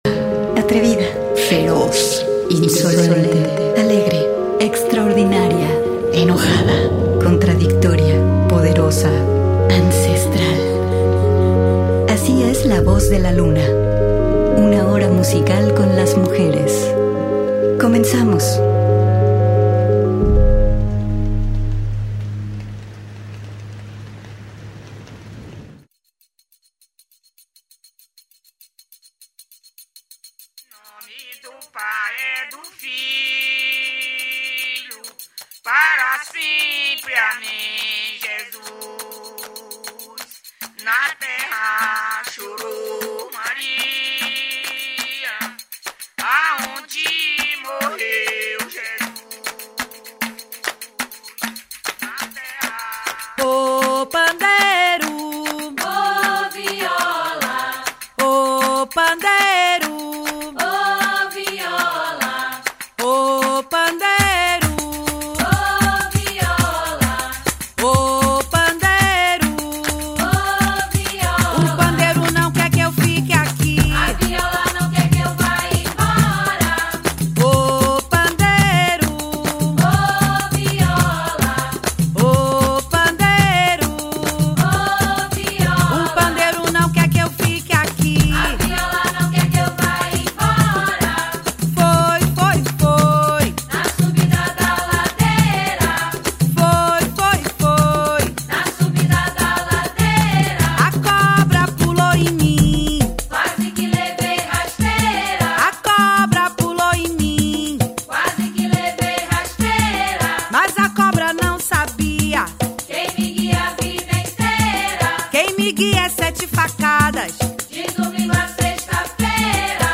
escuchando: música que hacen las mujeres en todas las latitudes del planeta